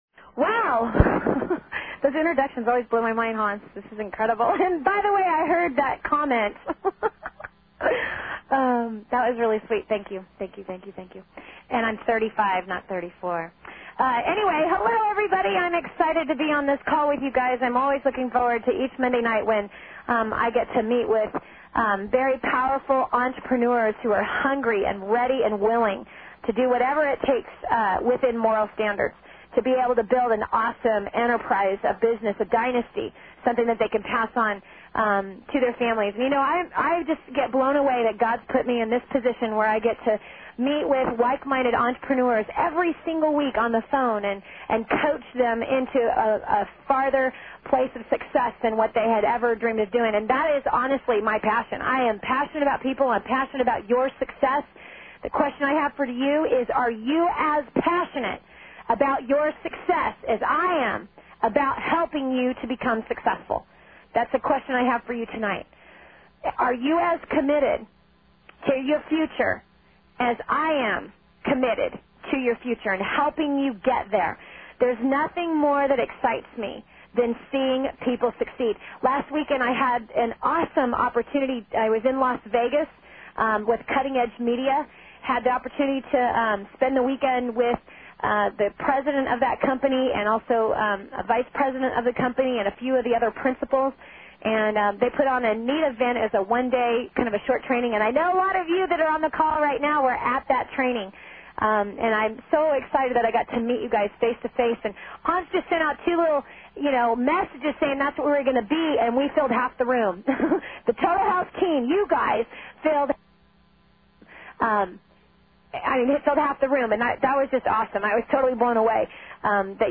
Questions from callers and more!